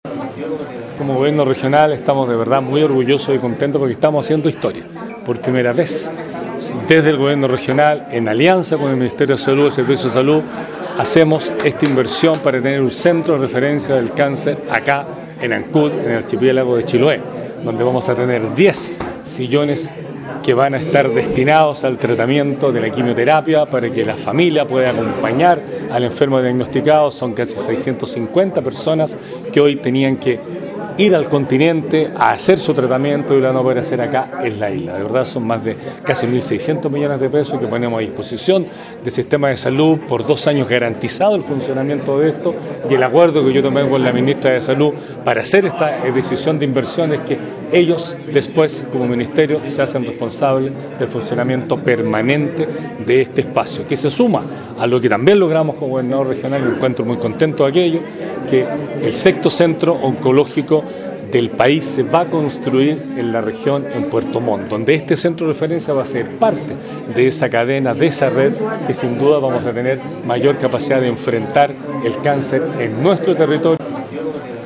El gobernador regional Patricio Vallespín sostuvo que el trabajo en conjunto permitió dar este paso relevante en la atención a una enfermedad que presentan grandes inconvenientes a la población de la región.